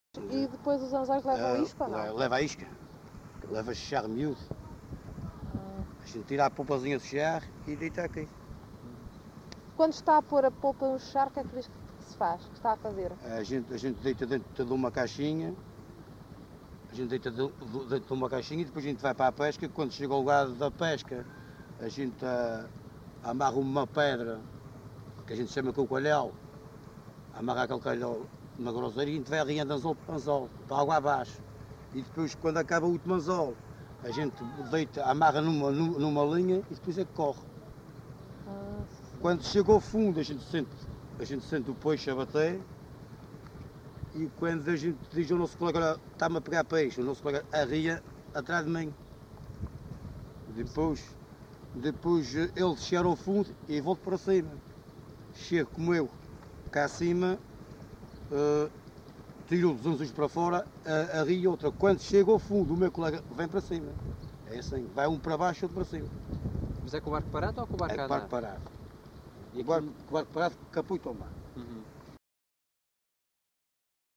LocalidadeSão Mateus da Calheta (Angra do Heroísmo, Angra do Heroísmo)